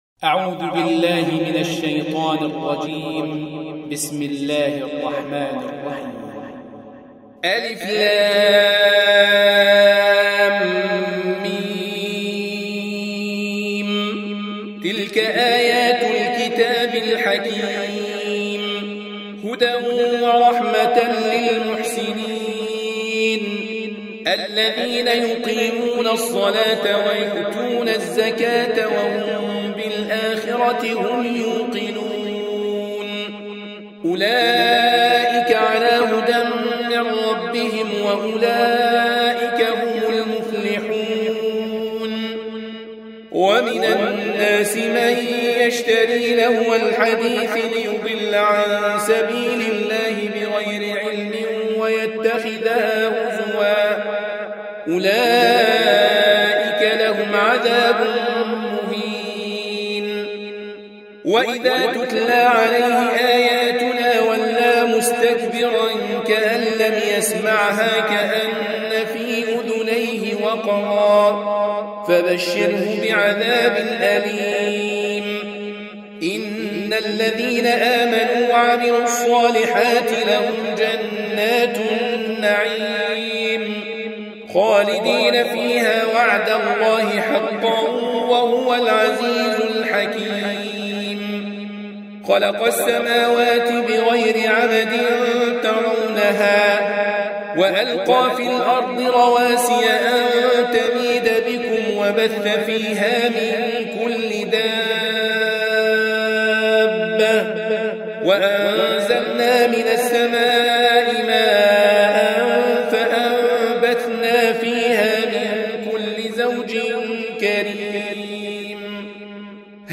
31. Surah Luqm�n سورة لقمان Audio Quran Tarteel Recitation
Surah Repeating تكرار السورة Download Surah حمّل السورة Reciting Murattalah Audio for 31. Surah Luqm�n سورة لقمان N.B *Surah Includes Al-Basmalah Reciters Sequents تتابع التلاوات Reciters Repeats تكرار التلاوات